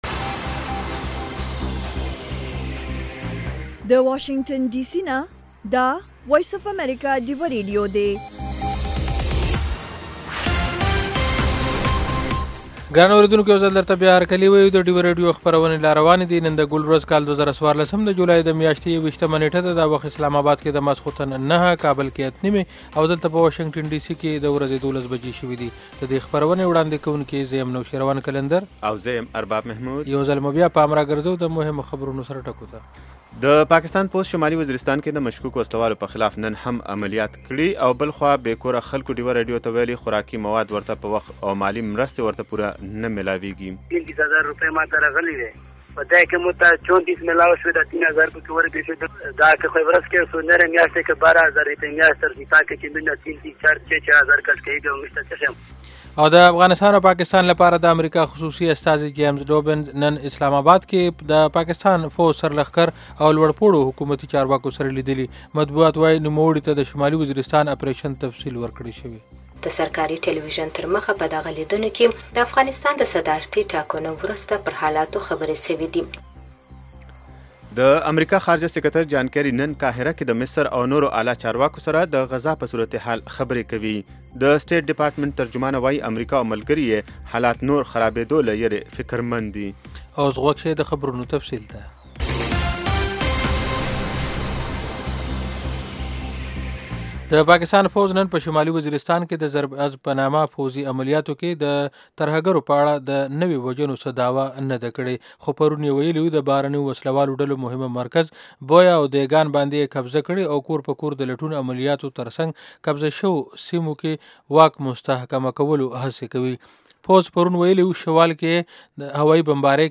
دا یو ساعته خپرونه خونده ورې سندرې لري میلمانه یې اکثره سندرغاړي، لیکوالان، شاعران او هنرمندان وي.